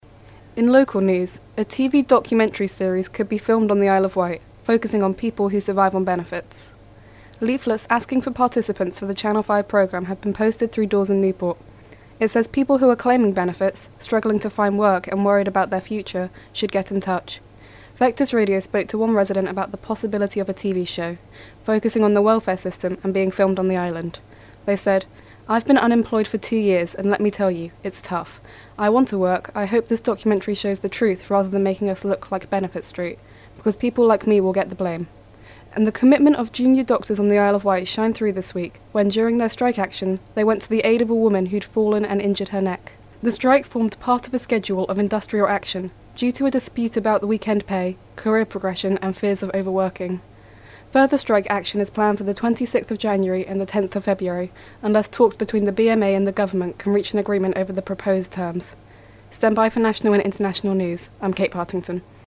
news reading